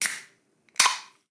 Abrir una lata de refresco
chapa
gas
Sonidos: Acciones humanas
Sonidos: Hogar